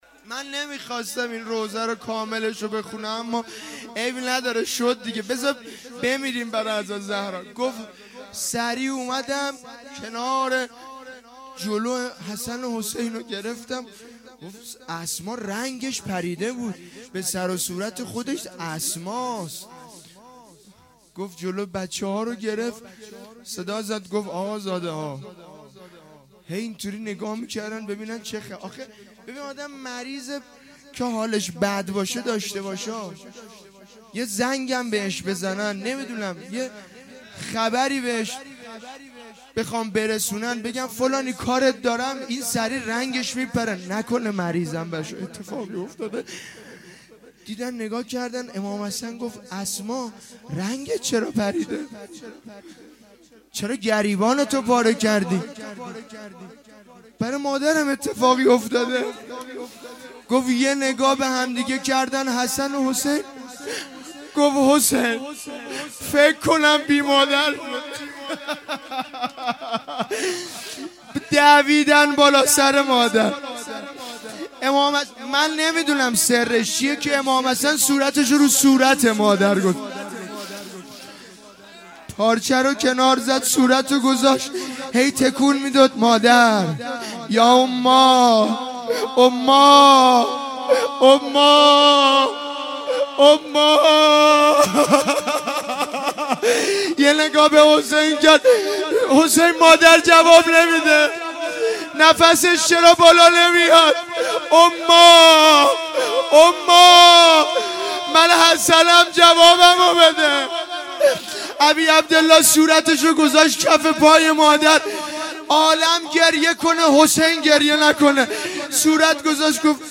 اقامه عزای روضه حضرت صدیقه شهیده علیها السلام _ شب اول